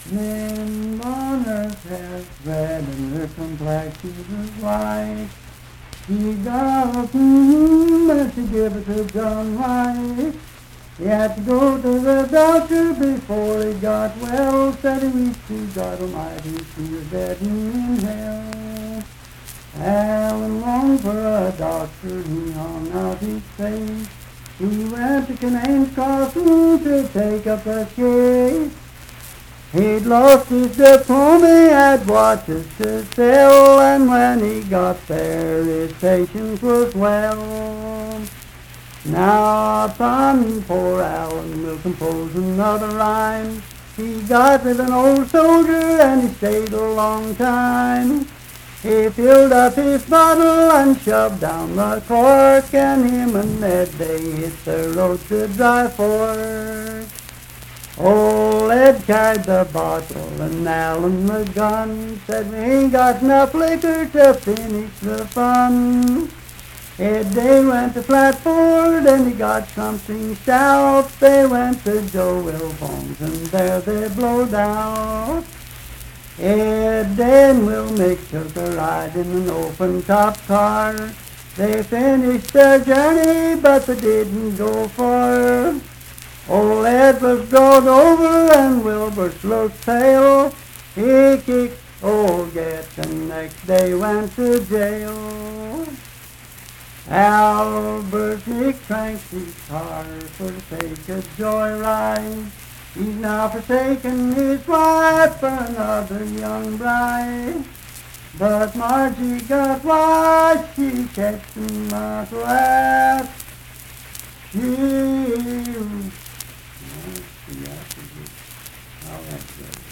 Unaccompanied vocal performance
Verse-refrain 7(4).
Voice (sung)